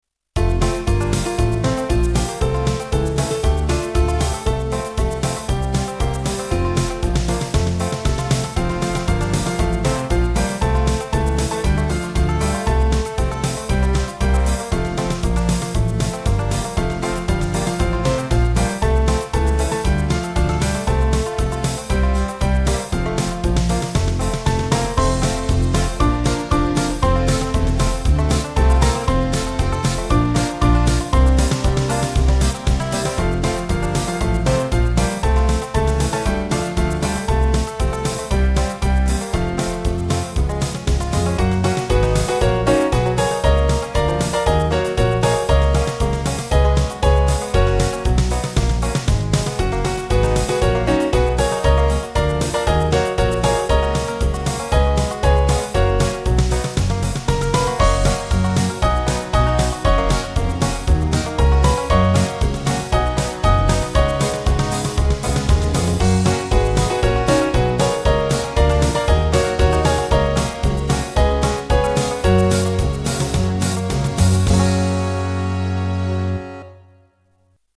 Small Band
(CM)   2/F-Gb 184kb